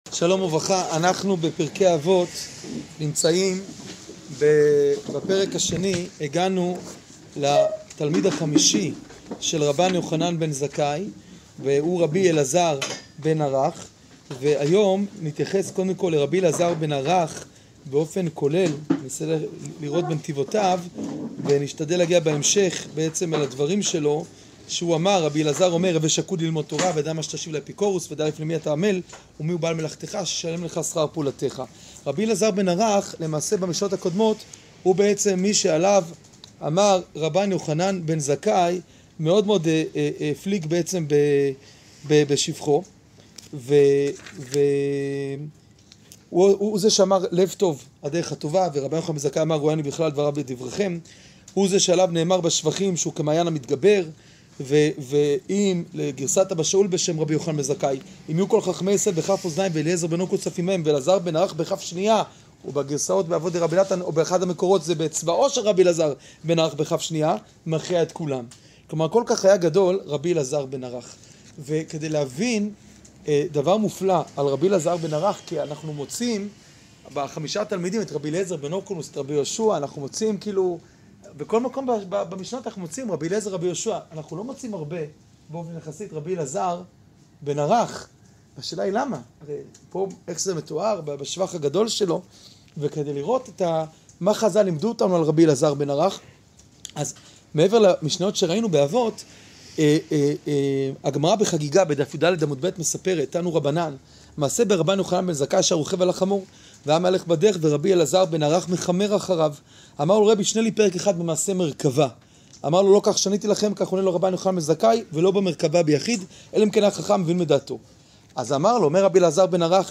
שיעור פרק ב משנה יד